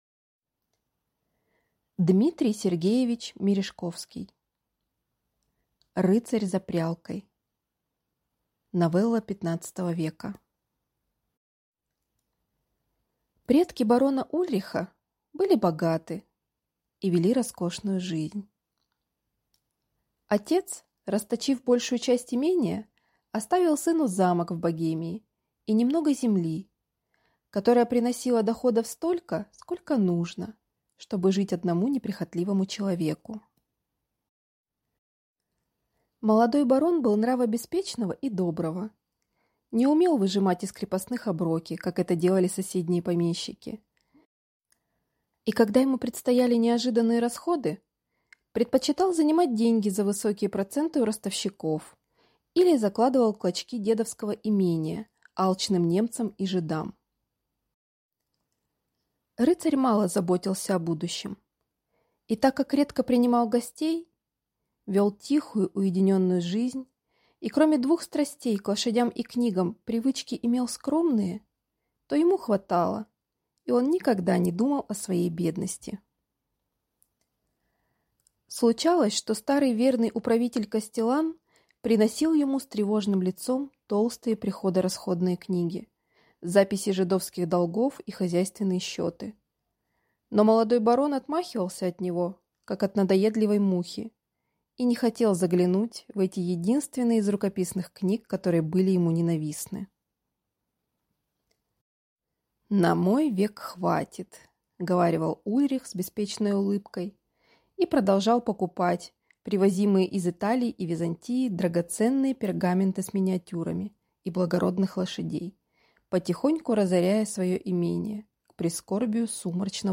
Аудиокнига Рыцарь за прялкой | Библиотека аудиокниг